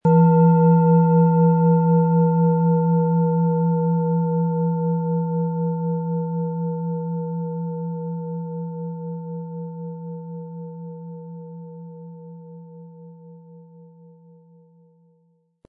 Planetenton 1
Mit viel Liebe und Sorgfalt in Handarbeit erstellte Klangschale.
Wie klingt diese tibetische Klangschale mit dem Planetenton Chiron?
Der passende Schlegel ist umsonst dabei, er lässt die Schale voll und harmonisch tönen.
MaterialBronze